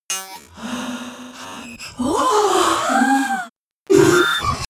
Royalty free sounds: Aliens